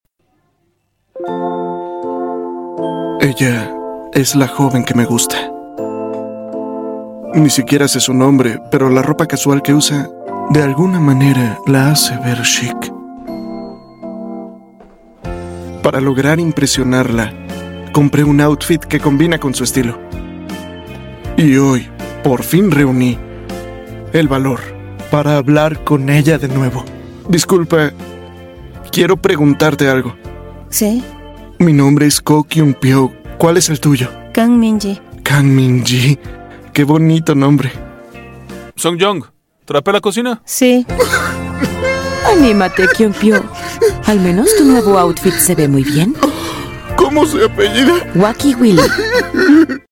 Un doblaje NO OFICIAL de los comerciales de la tienda de ropa coreana Wacky Willy.